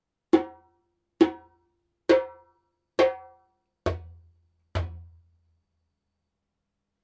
レンケらしい高音の伸び、倍音の響き。大口径のダイナミクス。